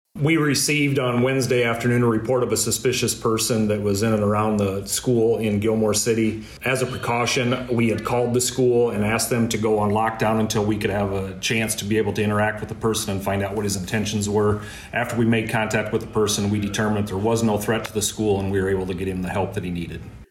Humboldt County Sheriff Chief Deputy Corey Lampe provided a statement from the Sheriff’s Office regarding the incident.